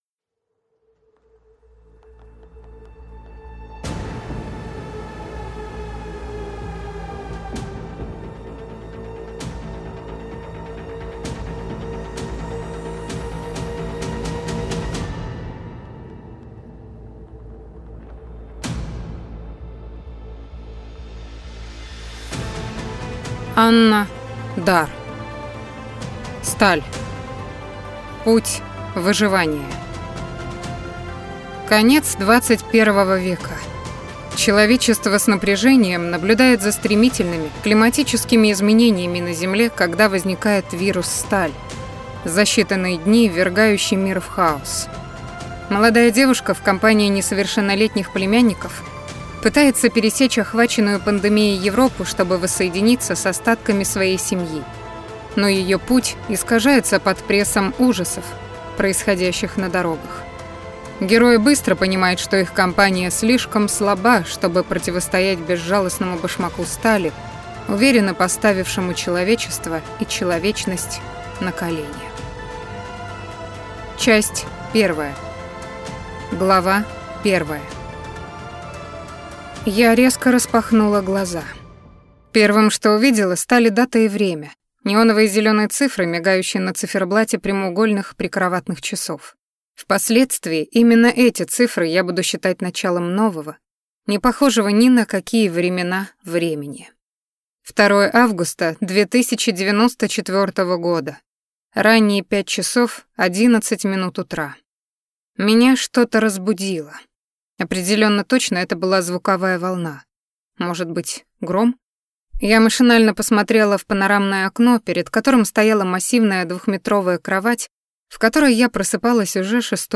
Аудиокнига Сталь | Библиотека аудиокниг
Прослушать и бесплатно скачать фрагмент аудиокниги